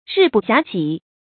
注音：ㄖㄧˋ ㄅㄨˋ ㄒㄧㄚˊ ㄐㄧˇ
日不暇給的讀法